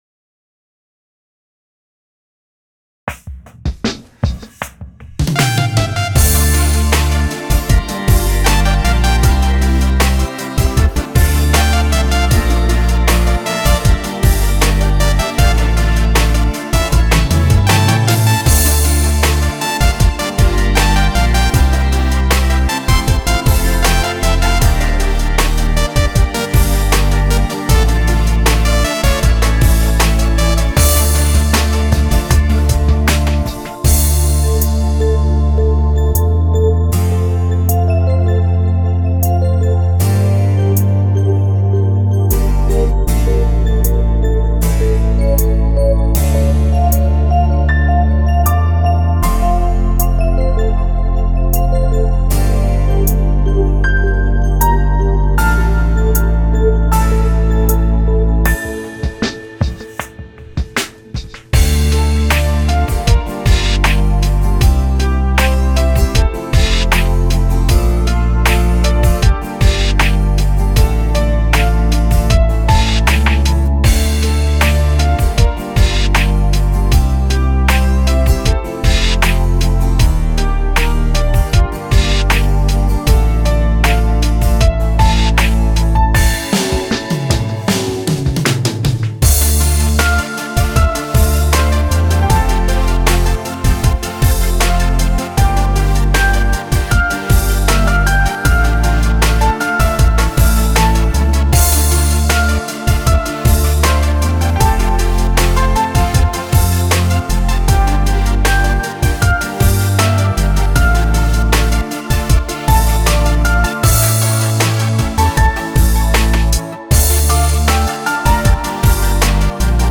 Скачать фонограмму (7.75 Mb) ]